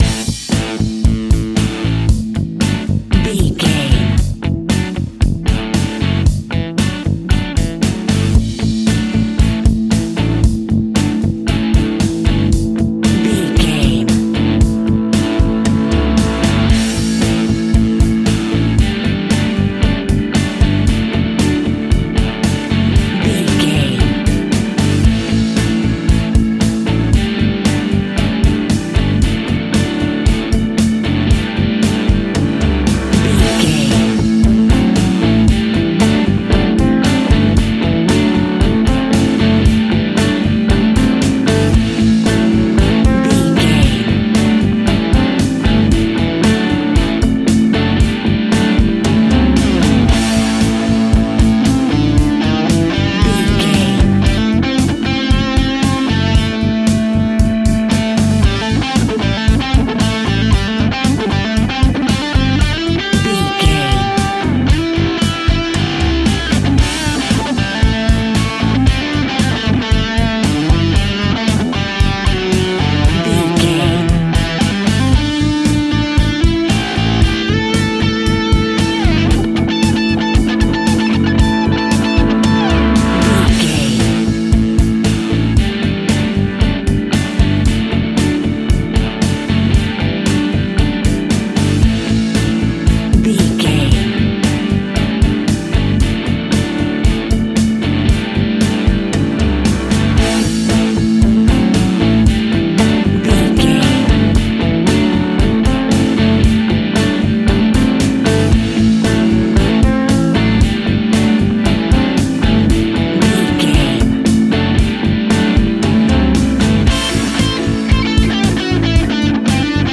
Ionian/Major
pop rock
indie pop
fun
energetic
uplifting
electric guitar
Distorted Guitar
Rock Bass
Rock Drums
hammond organ